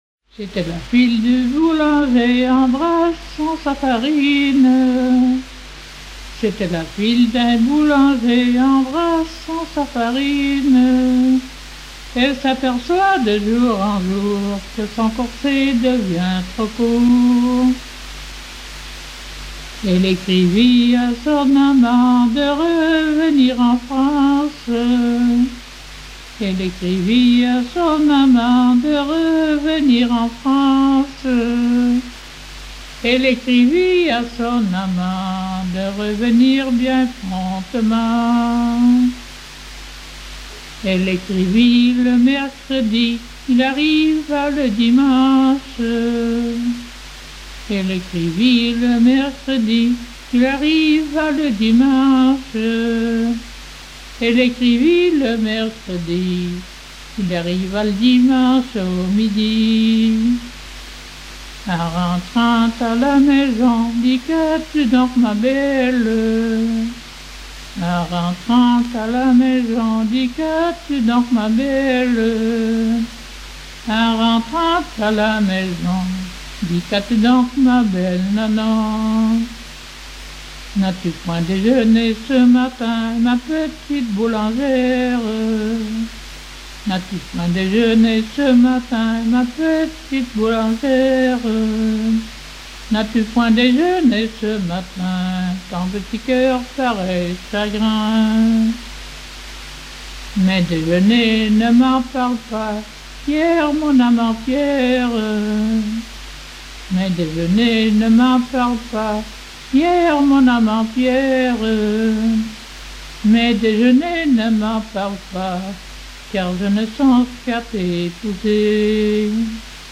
Localisation Coëx
Genre laisse